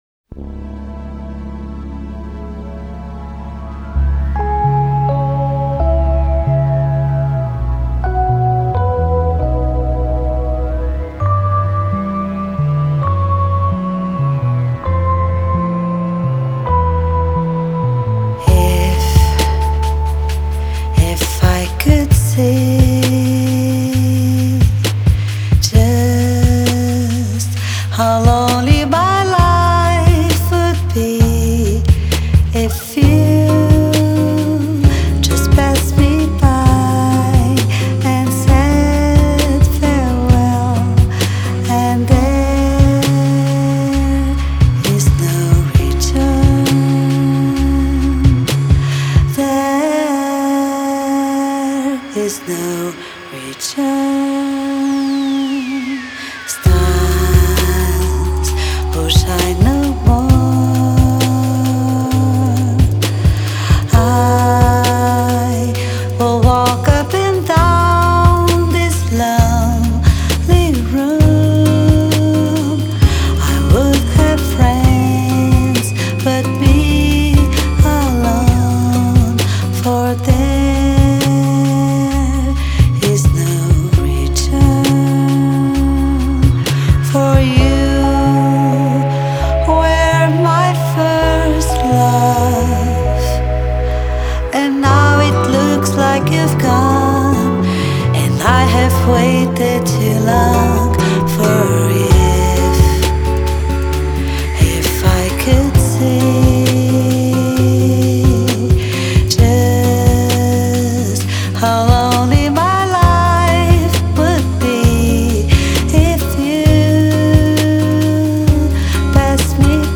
MPB